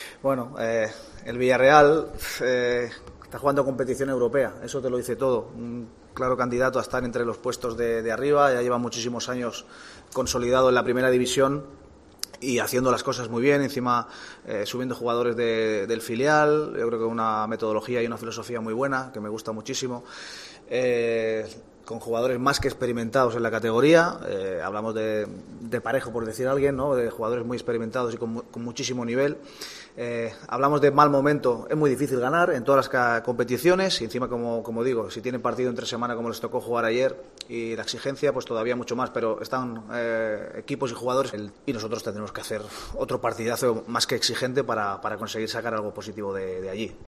García Pimienta compareció este viernes en la sala de prensa de la Ciudad Deportiva, donde analizó el partido que los amarillos tienen que jugar ante el Villarreal CF, este domingo.